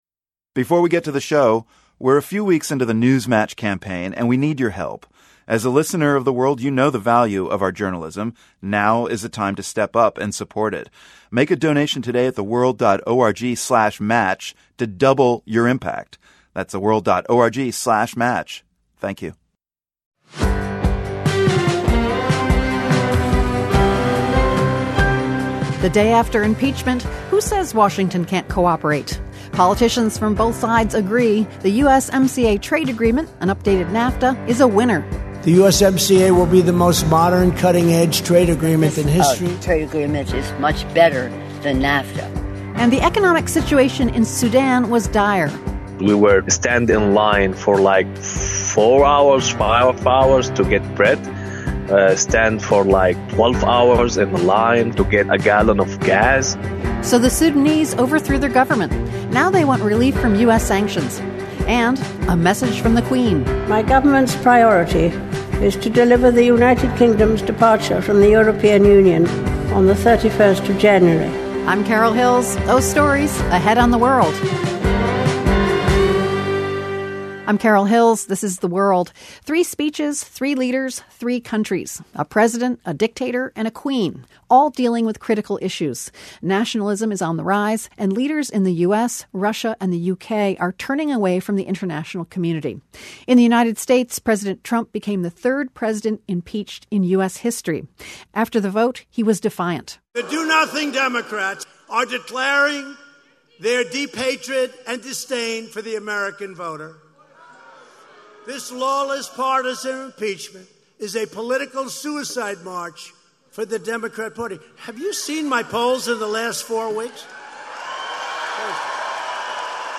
The World is a public media news program that relies on the support of listeners like you.